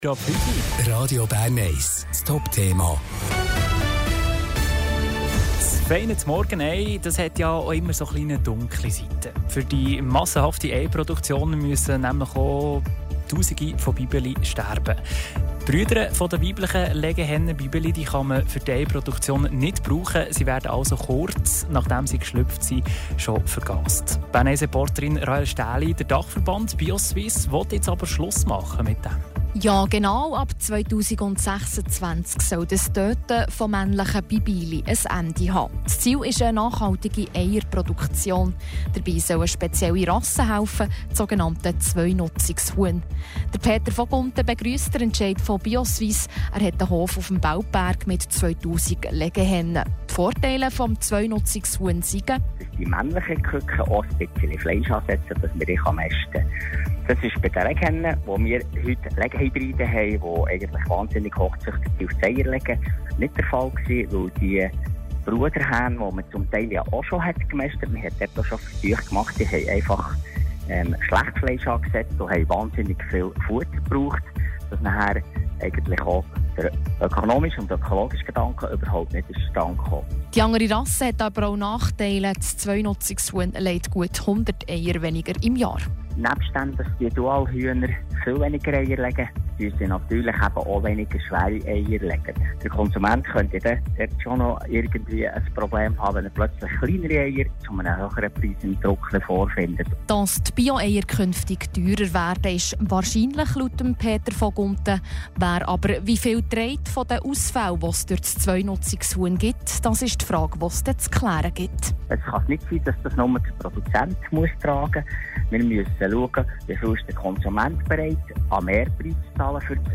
Bio Suisse will in Zukunft keine männlichen Küken mehr vergasen. Sie setzen auf das sogenannte Zwei-Nutzungshuhn. Ein Berner Eierproduzent erklärt die Vor- und Nachteile dieser speziellen Rasse.